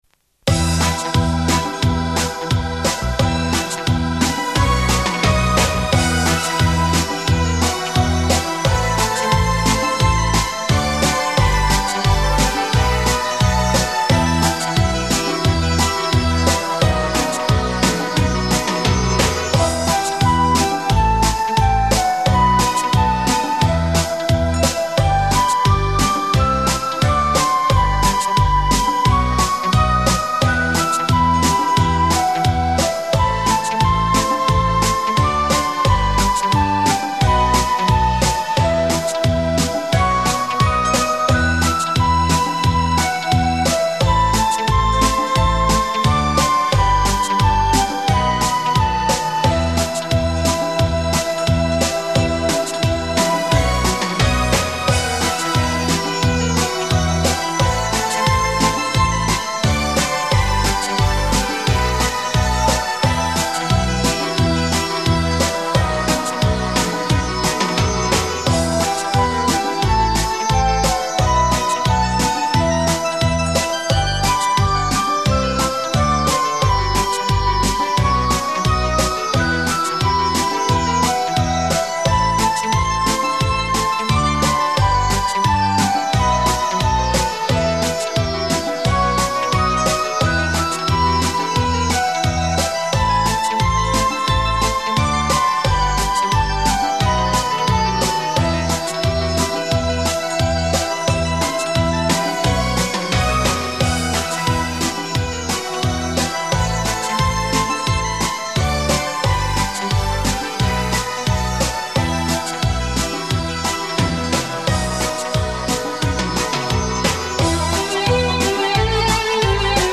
本专辑是用器乐演奏来表现大众所喜闻乐见的舞曲节奏音乐，
音质上乘佳作，是您在聆听中美的享受，
试听舞曲
和缓的节奏，舒心的音调，感谢了。